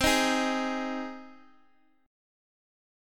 Cm#5 chord